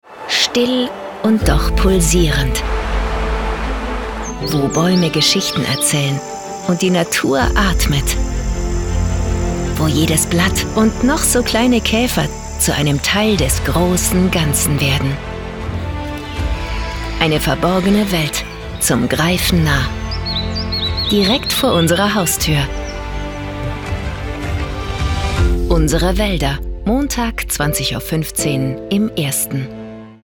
Stationvoice für Deinen Sender.
FEMALE GERMAN VOICE ARTIST and Voice Actress
My personal recording studio allows your audio file to be recorded very easily and guarantees the highest audio quality.